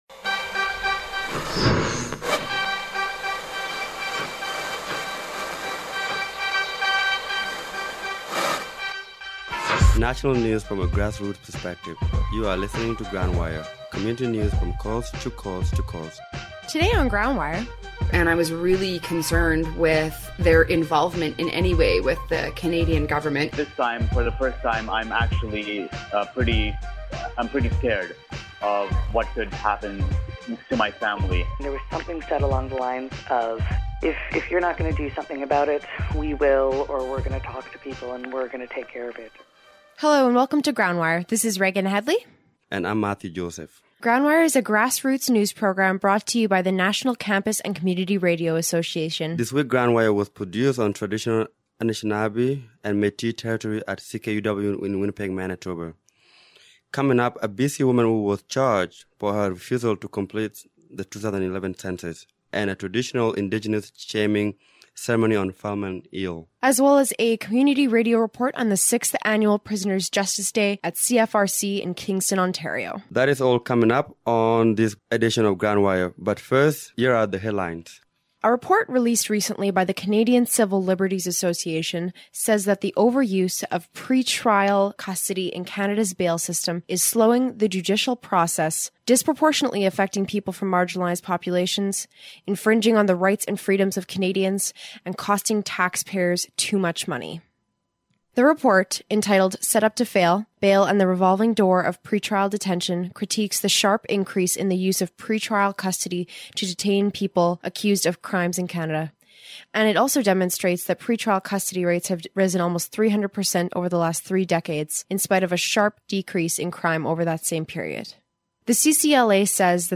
National Community Radio News